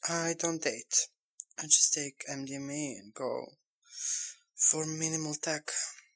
Tag: 150 bpm Techno Loops Vocal Loops 1.04 MB wav Key : Unknown